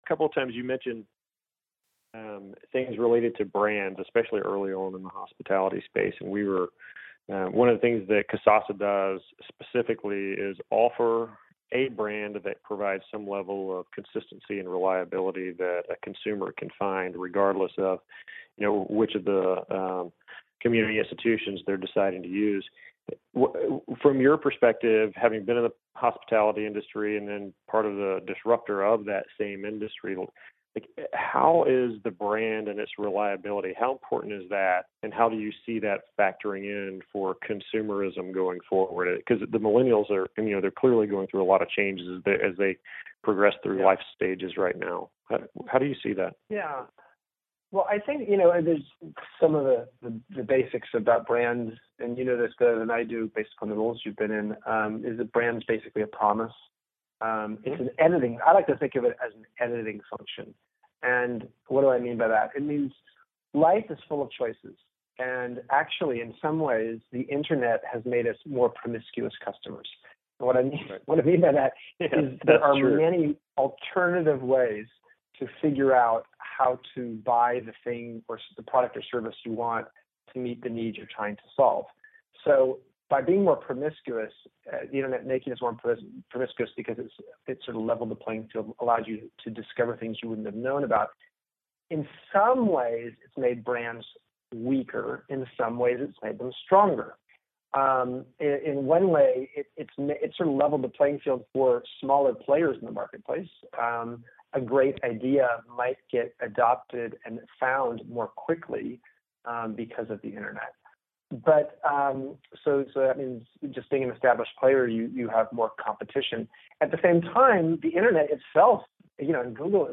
Listen to part three of our interview with Chip Conley here, or read our summary below: